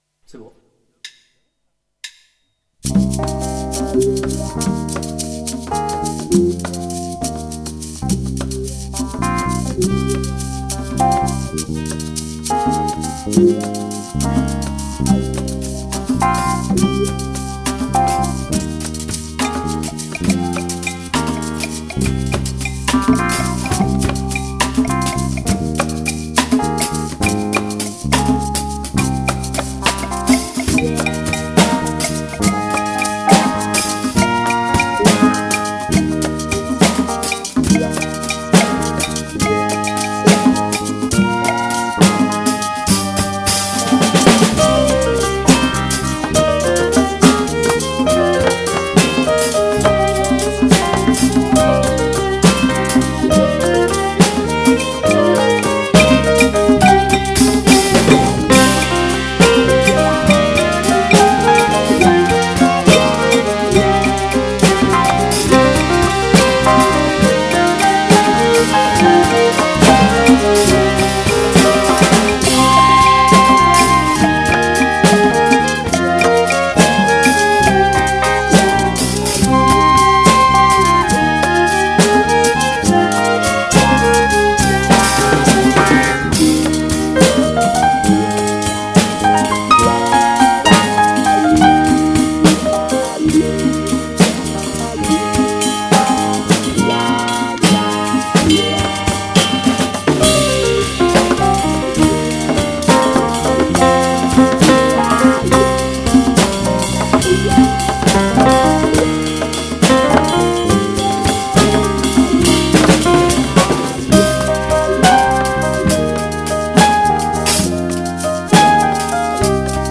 en 6/8
Structure AABA'
A : Chorus Piano + Melodica
A : Chorus Guitare + Chant
B : Thème Flutes B + violons